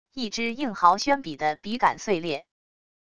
一支硬毫宣笔的笔杆碎裂wav音频